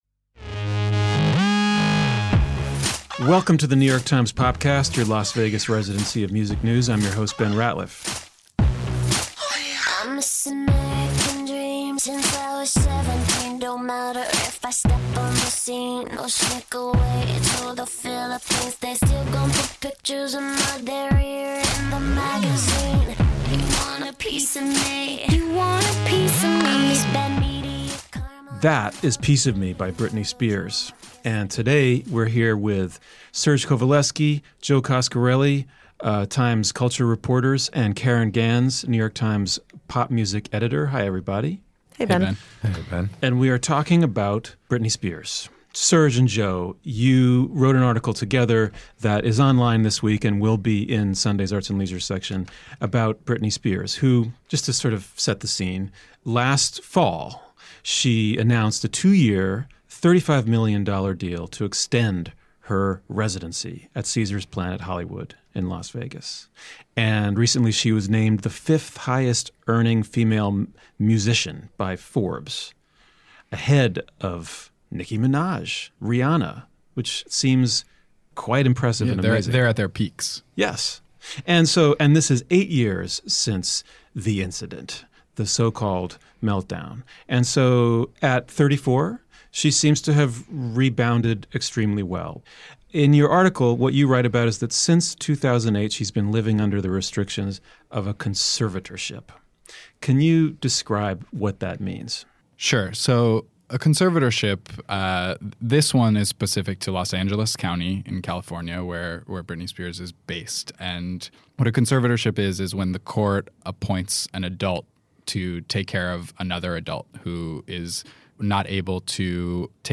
A discussion of Ms. Spears’s conservatorship.